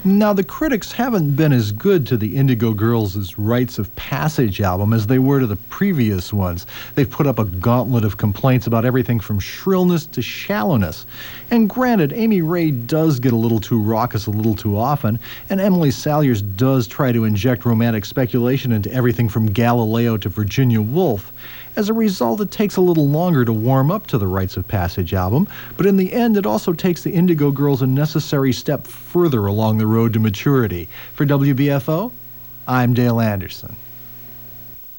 lifeblood: bootlegs: 1992-xx-xx: wbfo - buffalo, new york
07. reviewer (0:35)